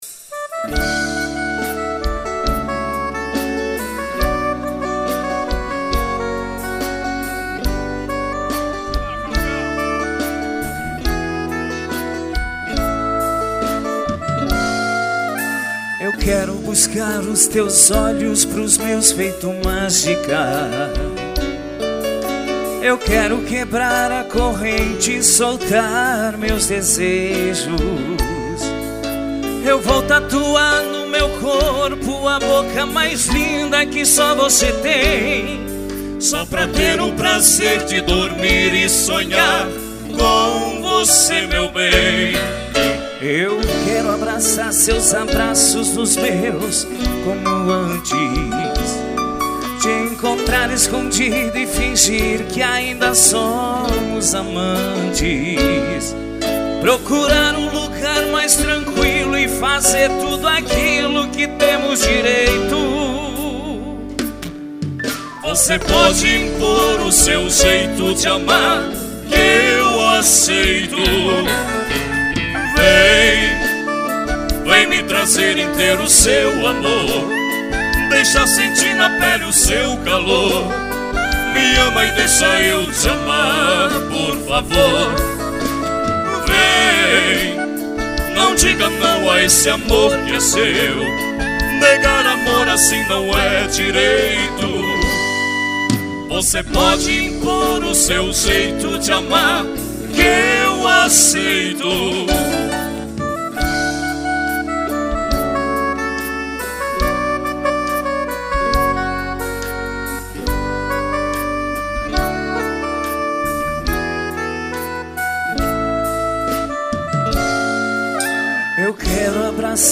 FESTIOESTE 2016 - Etapa Final